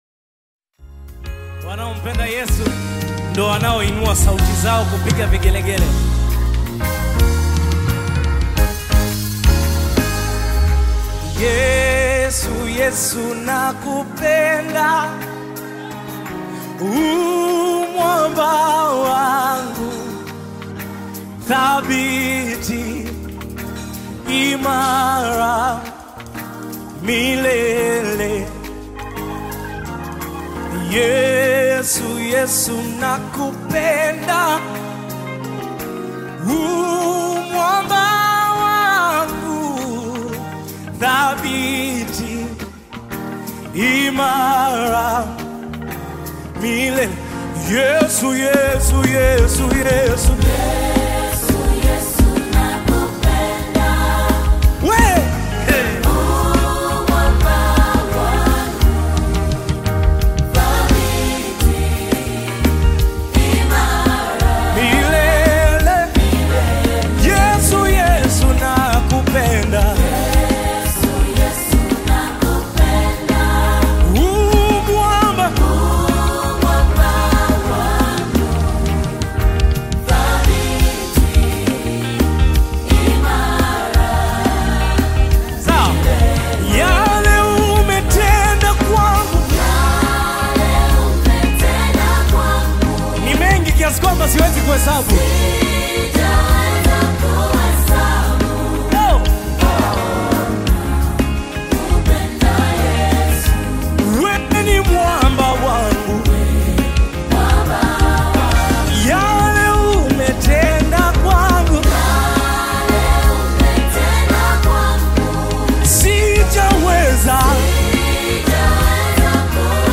Gospel music track
Tanzanian Gospel artist, singer, and songwriter